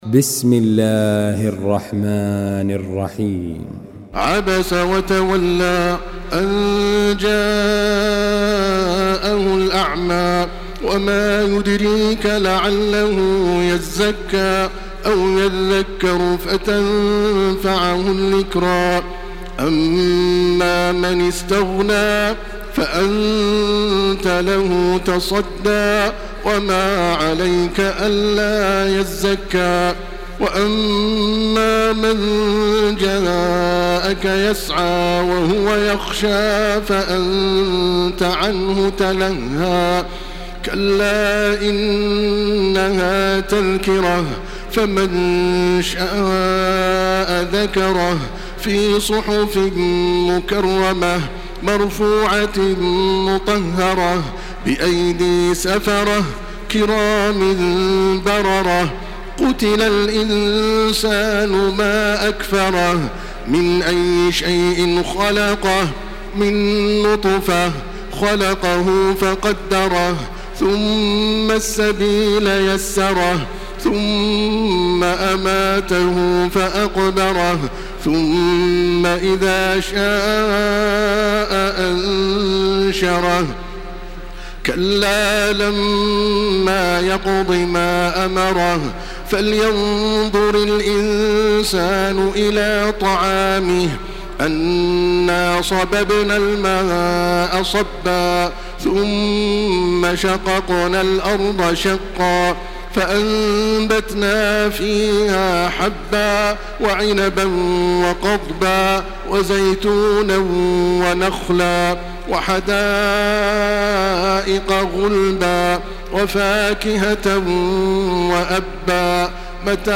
Surah عبس MP3 by تراويح الحرم المكي 1429 in حفص عن عاصم narration.
مرتل